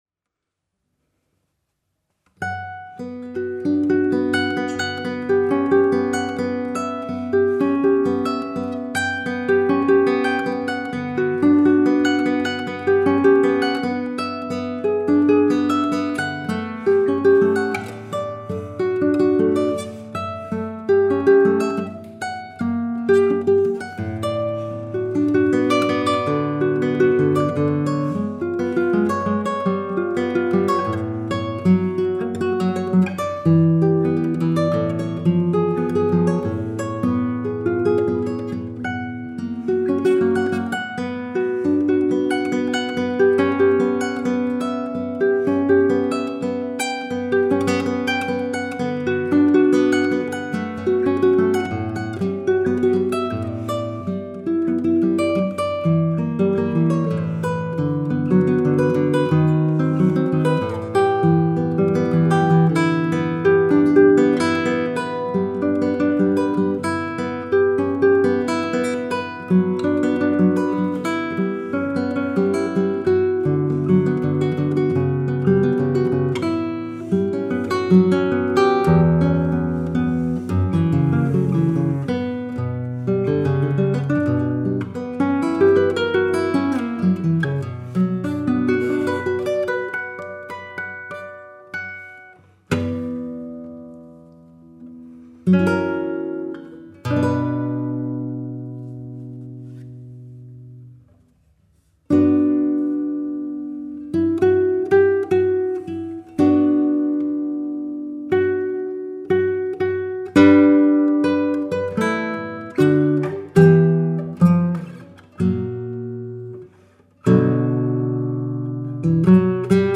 Un spectacle coloré par les notes de la guitare classique .